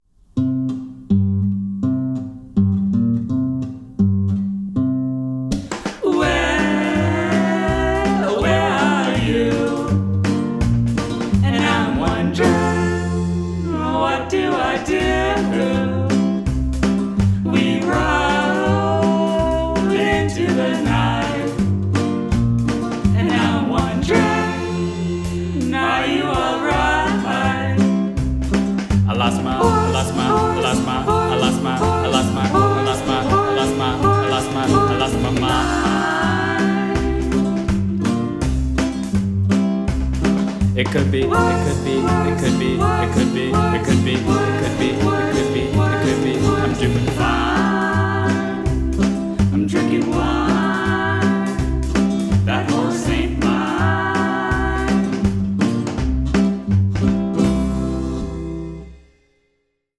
I wrote a new cowboy song, "I lost my horse"